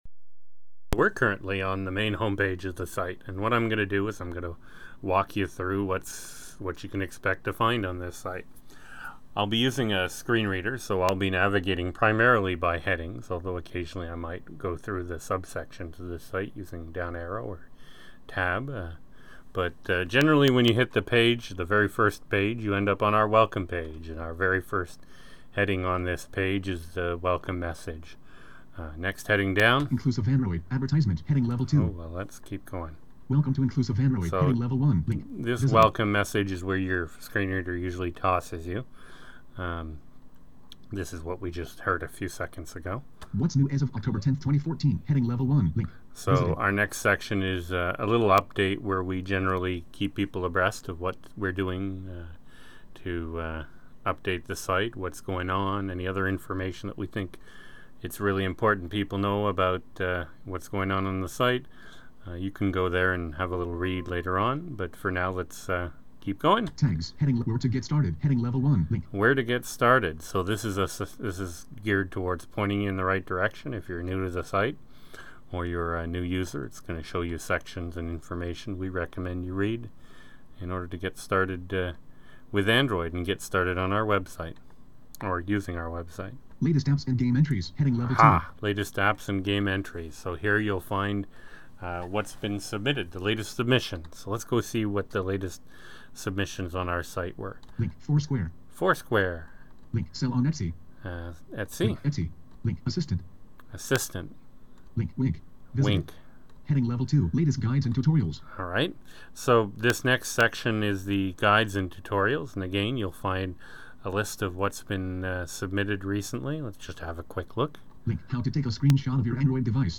Here is a rough walk through of the inclusive android website that was recorded in October of 2014. It walks a you through the site using a screen reader.